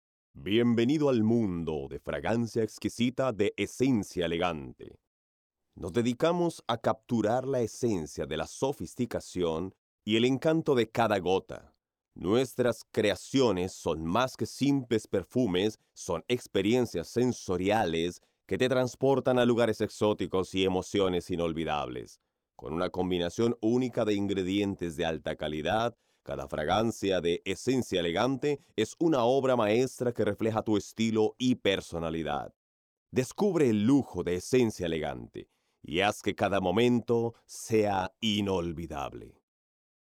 Experto en locución con tono versátil y cautivador.
kastilisch
Sprechprobe: Industrie (Muttersprache):
Expert in versatile and captivating voiceovers.